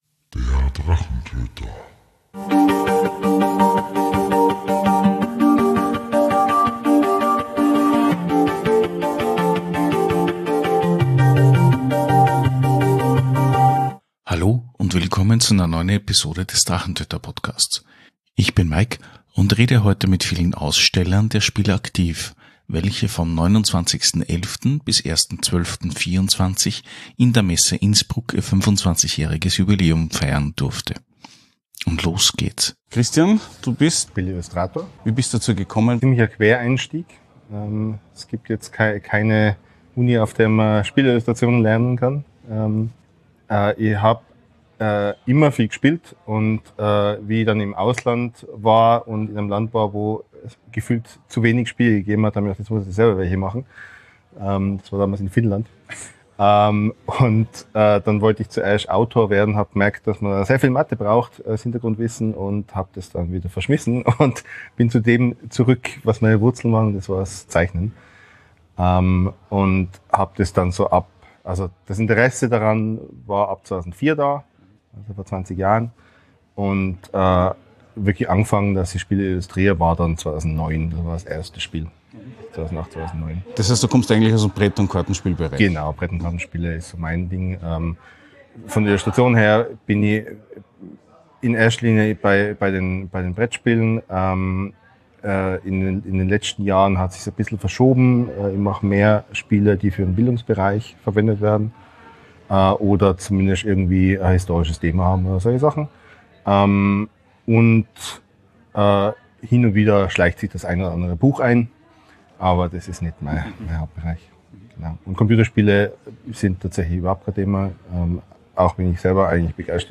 - Die Interviews 58:25 Play Pause 1d ago 58:25 Play Pause נגן מאוחר יותר נגן מאוחר יותר רשימות לייק אהבתי 58:25 Spiel Aktiv!